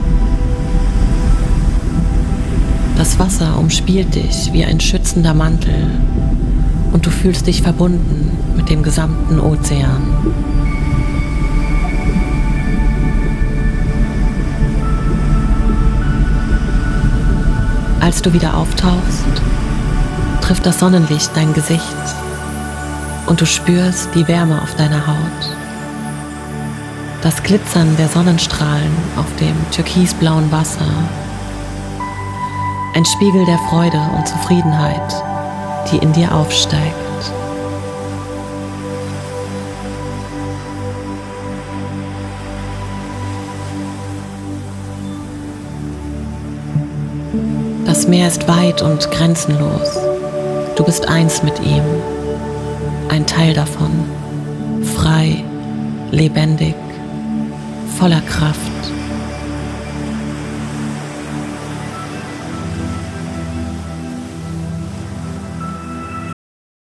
Frequenz: 528 Hz – Fördert Transformation und innere Heilung.
8D-Musik: Versetzt dich in die Atmosphäre des Ozeans, verstärkt das Gefühl der Freiheit.